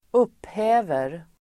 Uttal: [²'up:hä:ver]